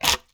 Close Combat Break Bone 3.wav